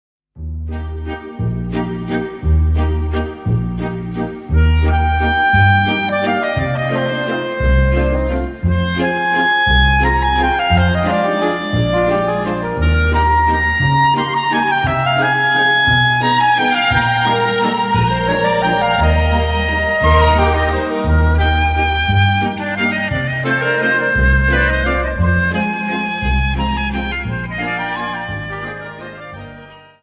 Dance: Viennese Waltz 58 Song